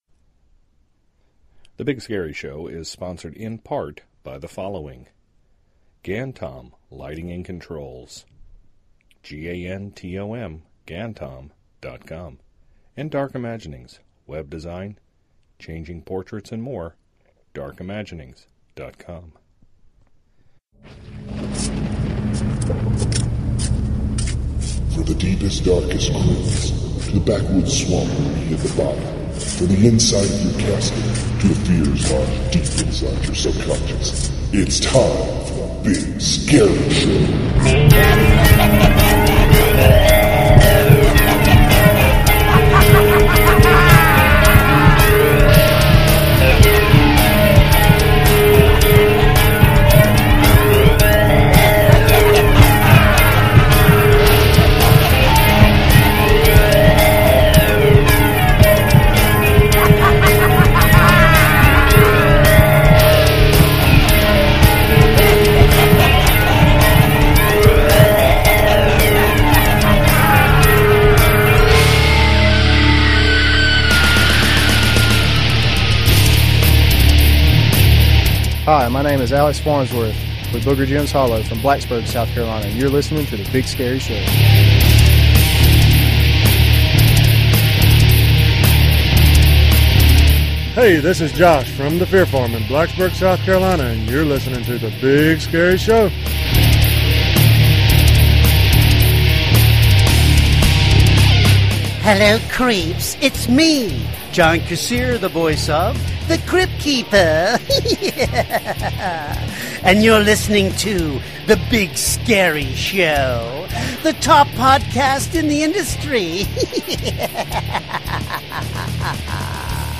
The Roundtable of Terror is reflecting on the 2016 season with the (g)hosts, as a funeral dirge marks the end of the season.